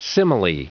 Prononciation du mot simile en anglais (fichier audio)
Prononciation du mot : simile